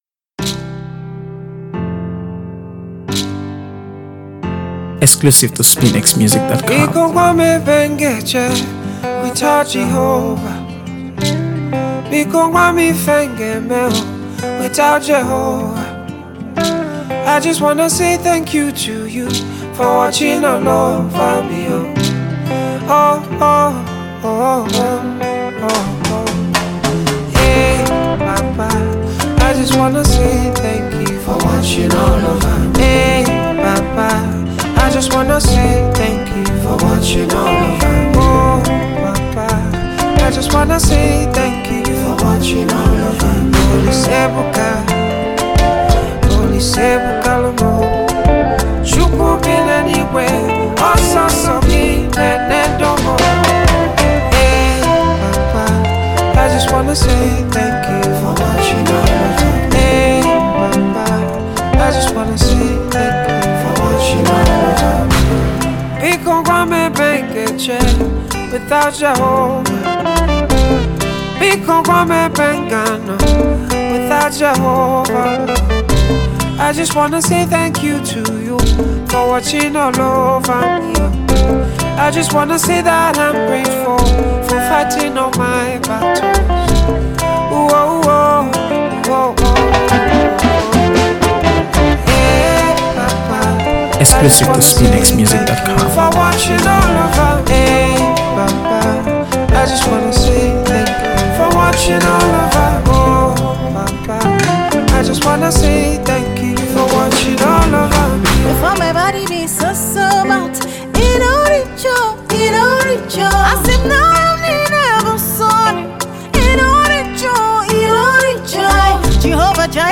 Afro-pop/R&B singer